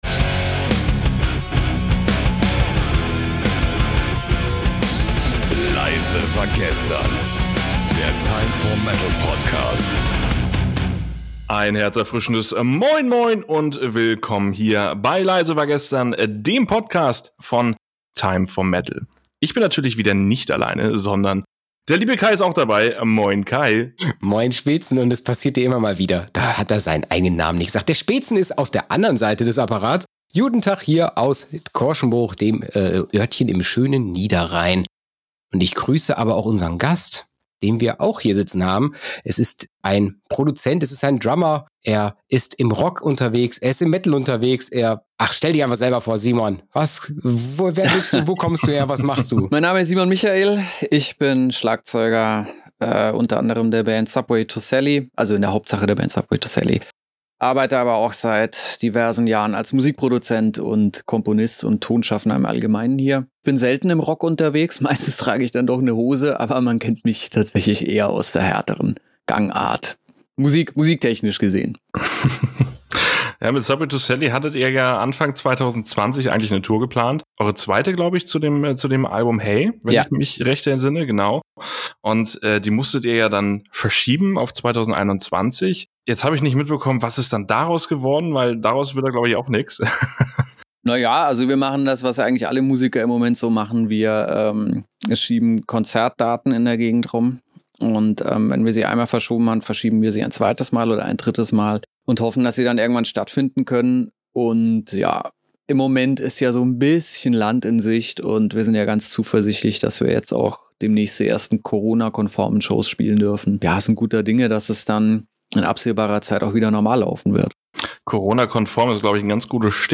Interview
Podcast-Studio